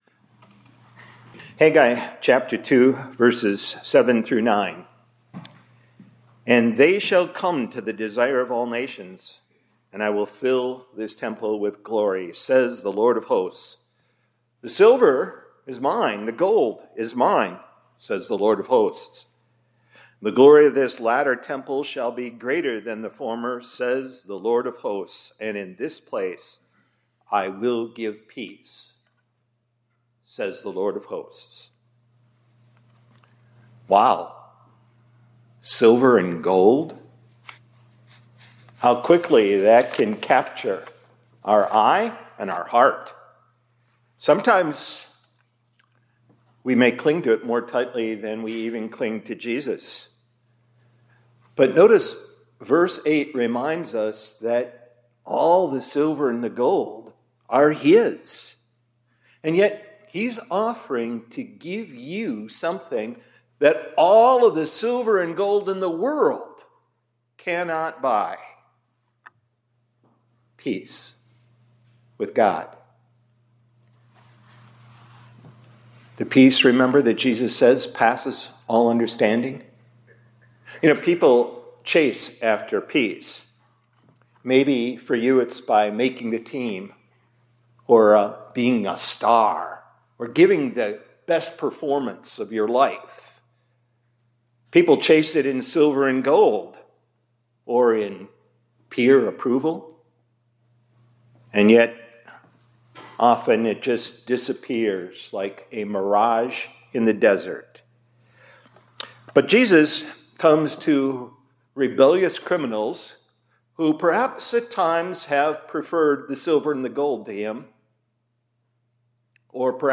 Chapel – Page 2 – Immanuel Lutheran High School, College, and Seminary